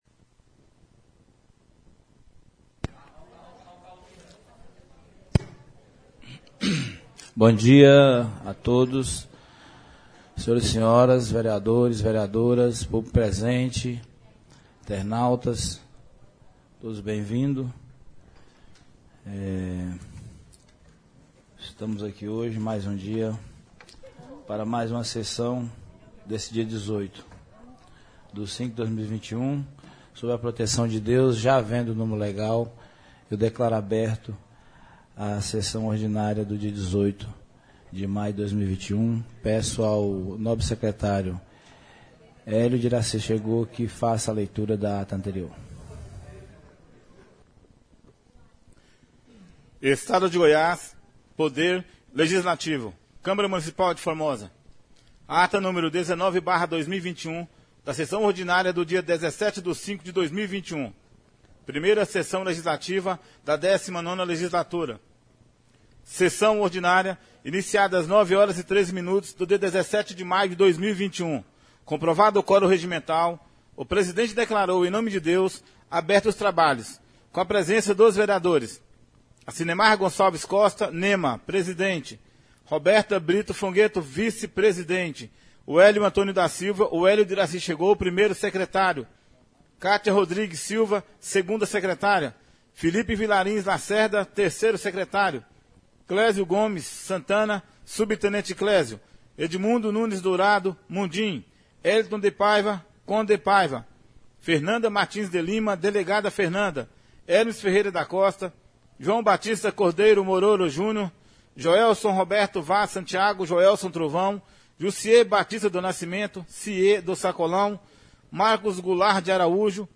18-05 Sessão Ordinária